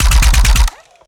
Added more sound effects.
GUNAuto_RPU1 Burst_06_SFRMS_SCIWPNS.wav